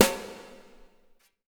BW BRUSH01-L.wav